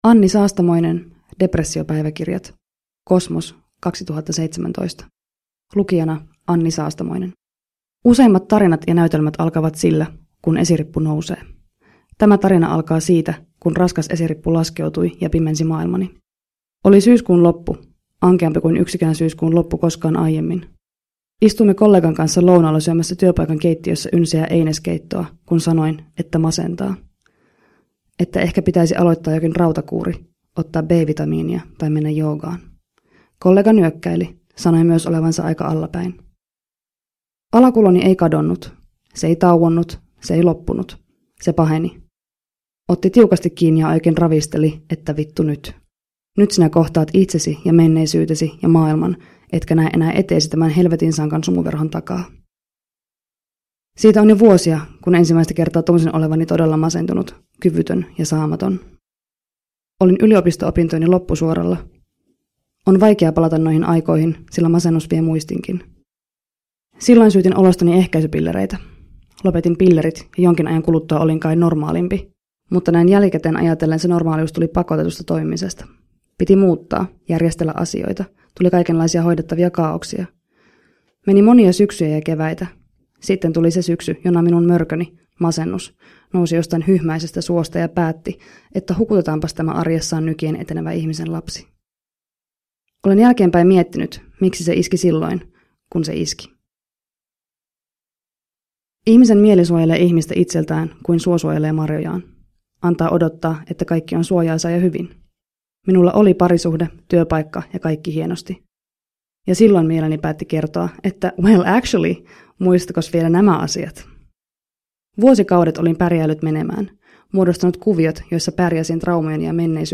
Depressiopäiväkirjat – Ljudbok – Laddas ner